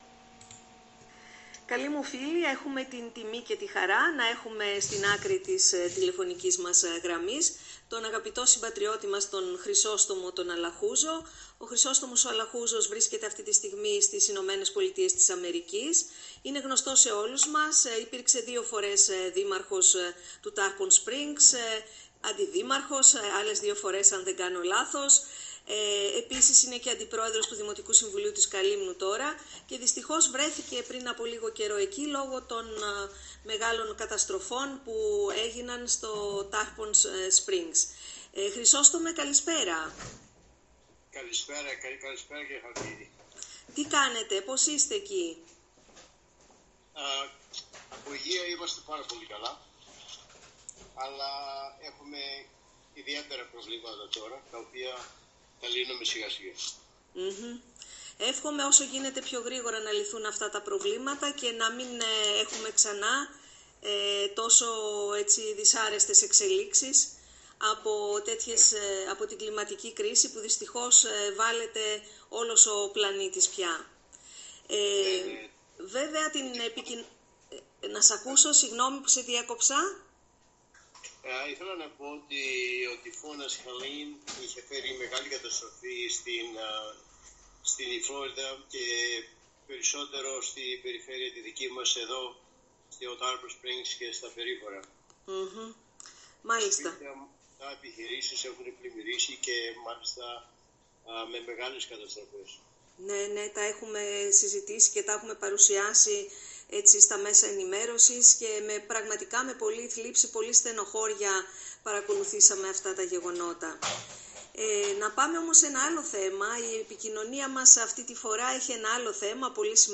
Ο συμπατριώτης μας Κρις Αλαχούζος, πρώην δήμαρχος του Tarpons Sprıngs και νυν αντιδήμαρχος του Δημοτικού Συμβουλίου της Καλύμνου σχολιάζει, από την Αμερική όπου βρίσκεται αυτό το διάστημα, τα αποτελέσματα των Αμερικανικών εκλογών, φανερά ικανοποιημένος από την μεγάλη νίκη του Ντόναλντ Τραμπ.